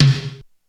Index of /90_sSampleCDs/300 Drum Machines/Korg DSS-1/Drums02/01
MedTom.wav